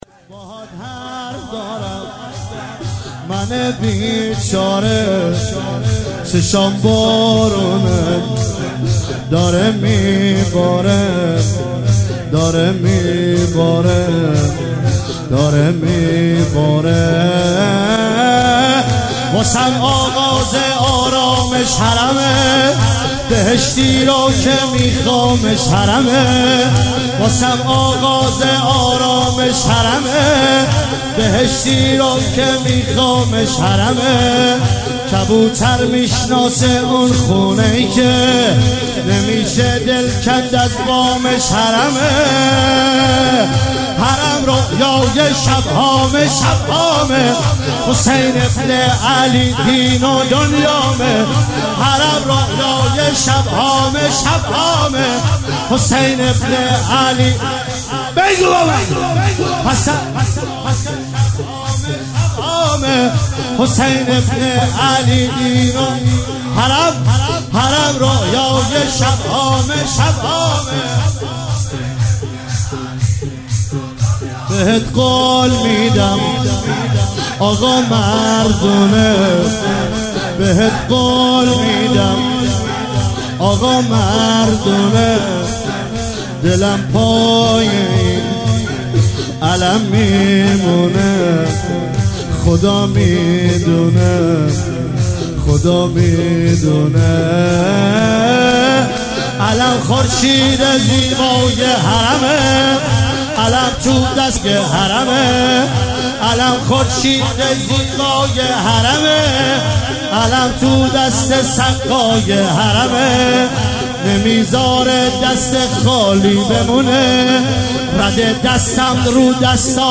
شور - باهات حرف دارم منه بیچاره